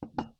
Ceramic on Wood 2.wav